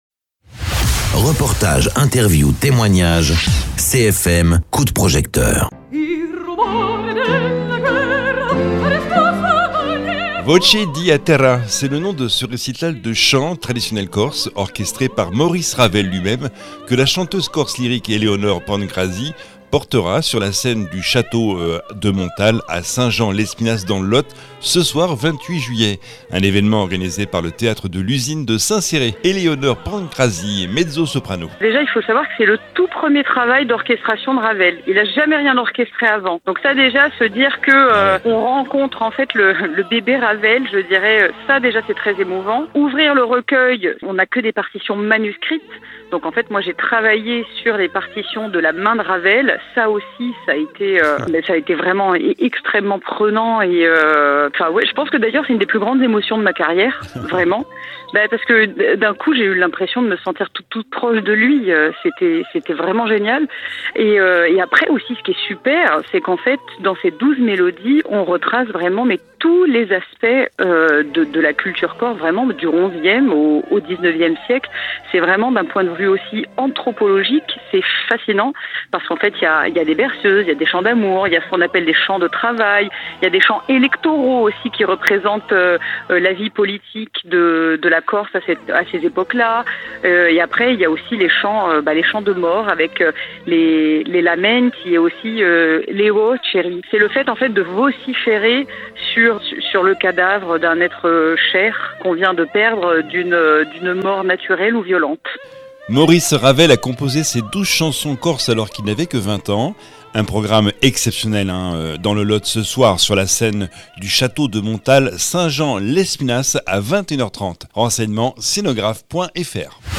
Interviews
mezzo-soprano